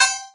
count_trophies_01.ogg